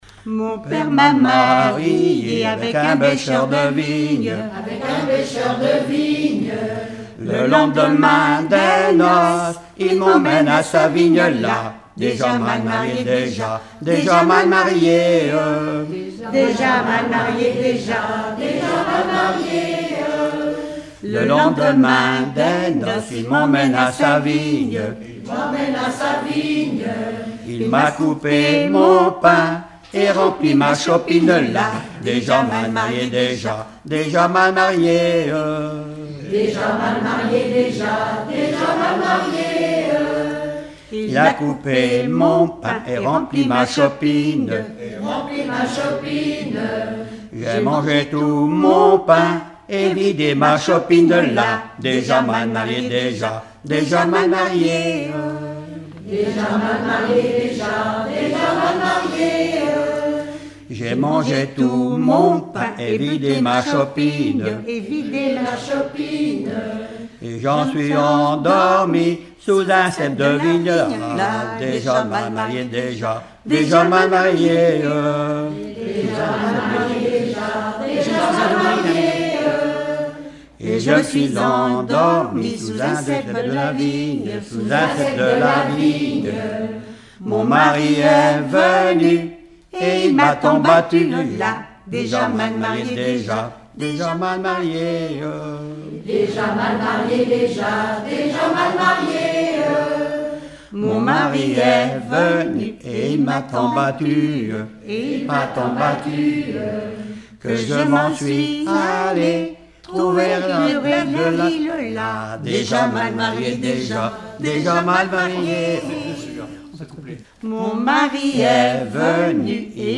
Regroupement de chanteurs du canton
Pièce musicale inédite